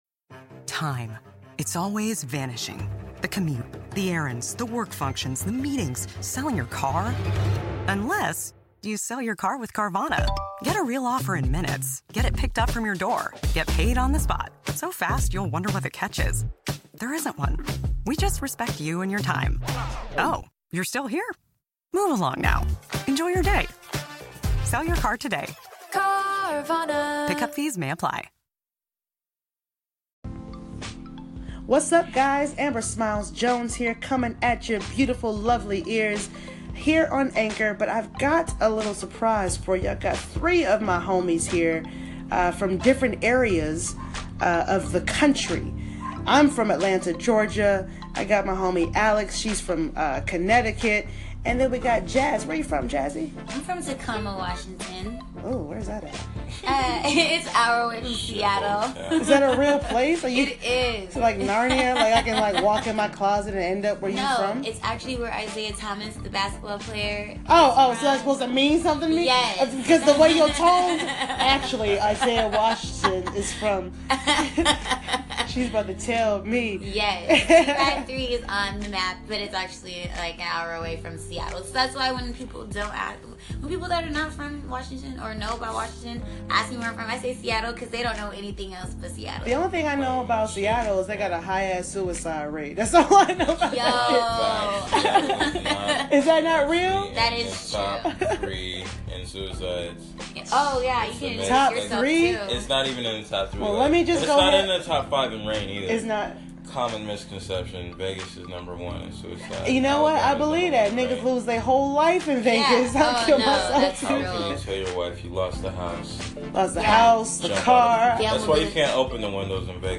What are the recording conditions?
I was chilling with my peeps in my apartment and we were having a deep conversation about how we ended up in LA and why.